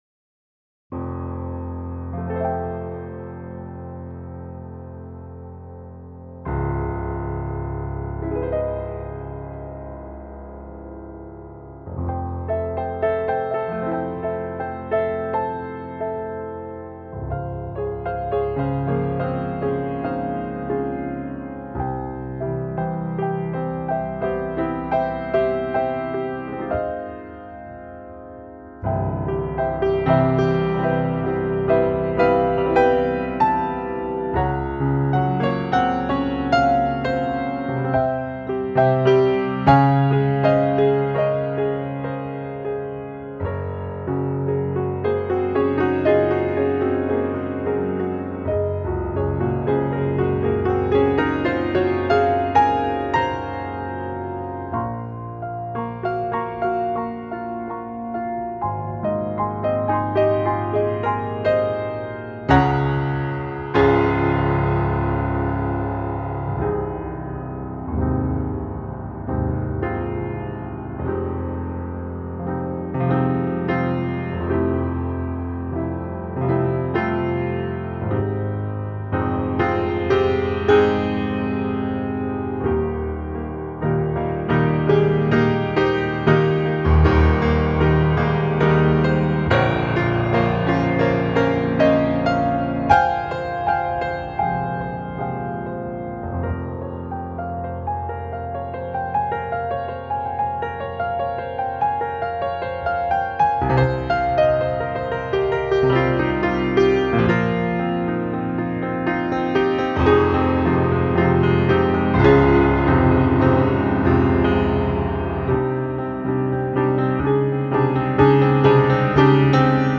Piano
Improvisation: